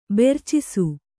♪ bercisu